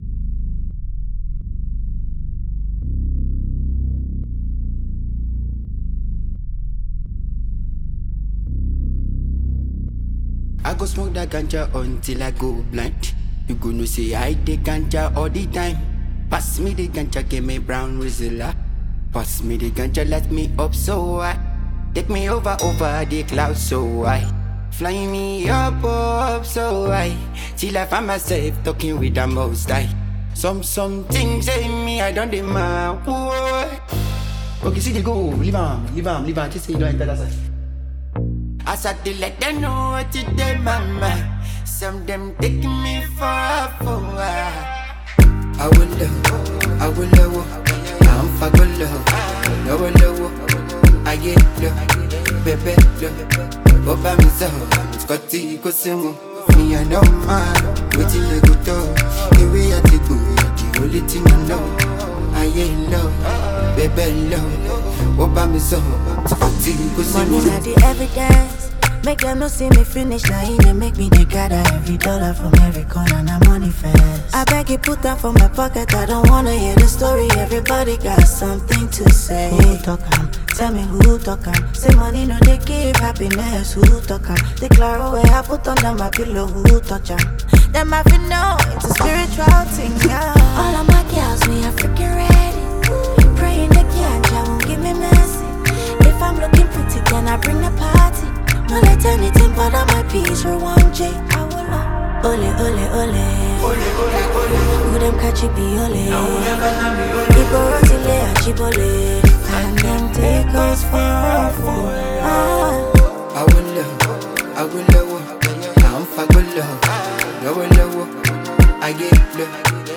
NAIJA MUSIC
high energy
catchy vibes